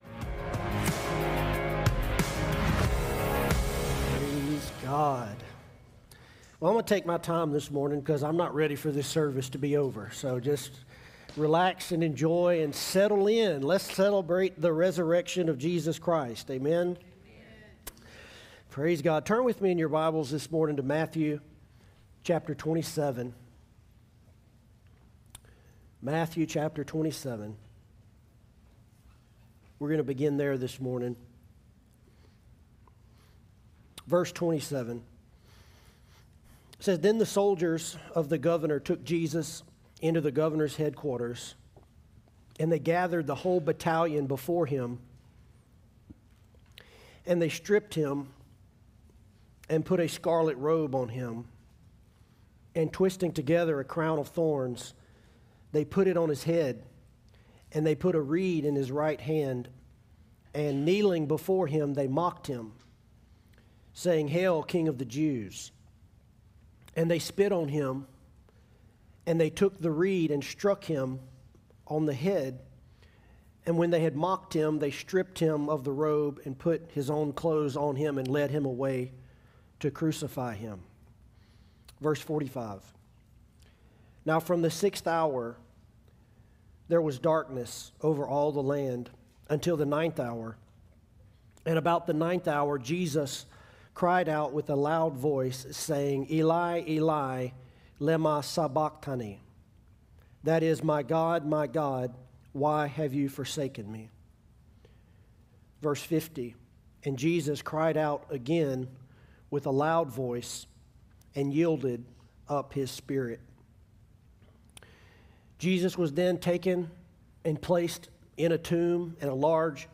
Sermons from One Life Church Alexandria